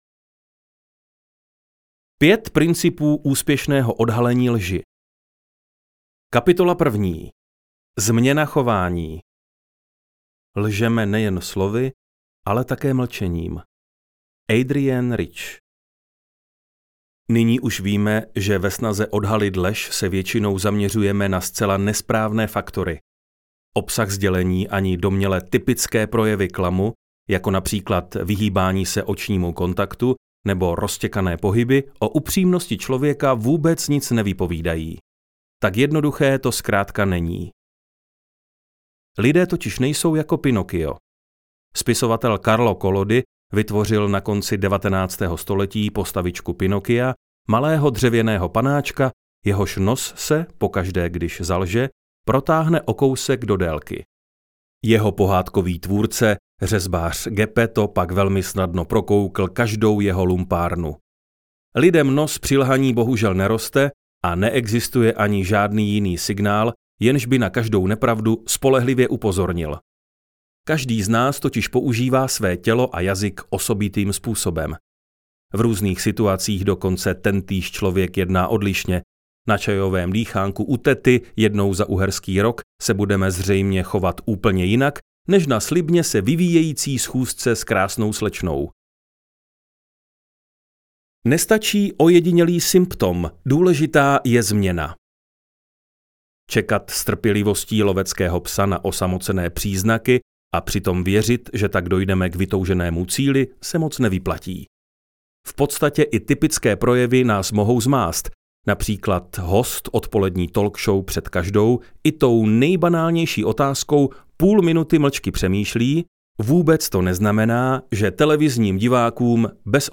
Jak prokouknout lež a odhalit pravdu audiokniha
Ukázka z knihy